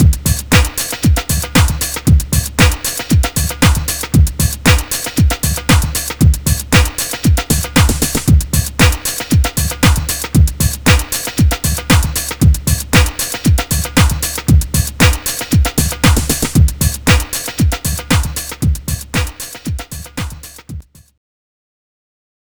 作ったリズムパターンはwavデータに保存できる。